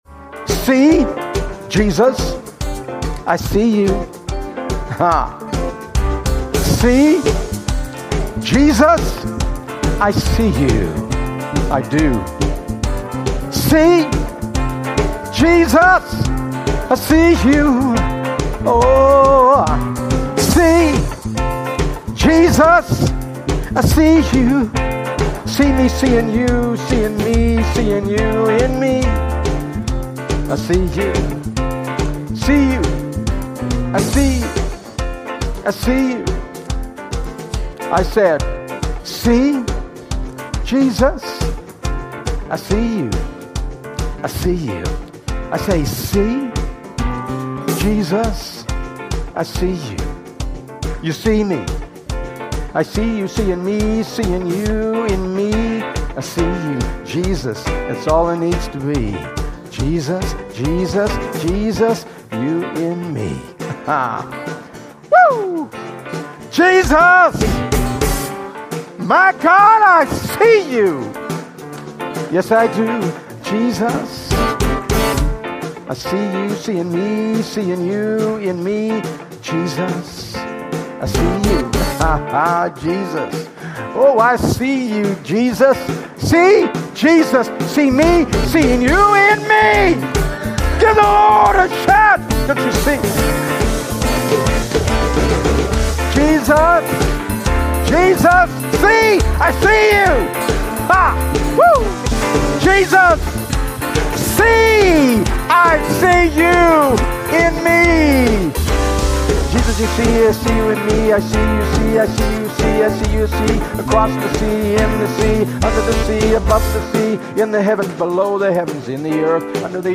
Sermon: Full Service: